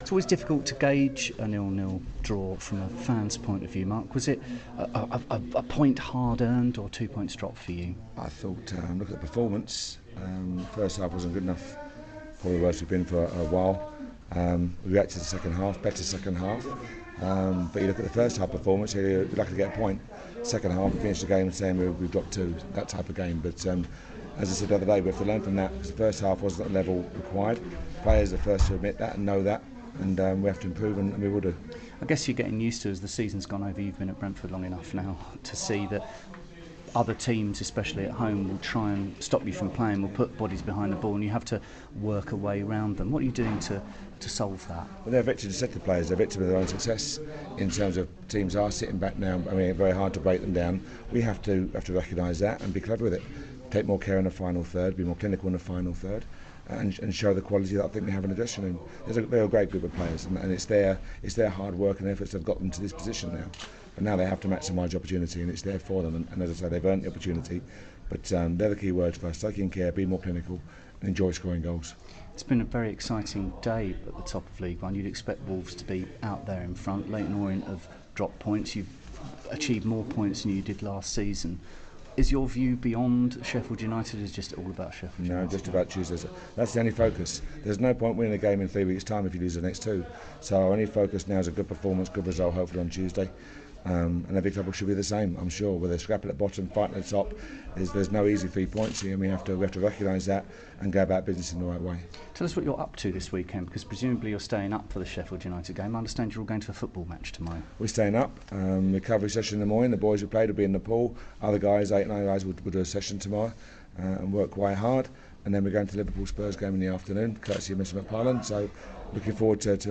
speaks to Brentford manager Mark Warburton following their 0-0 draw away at Oldham.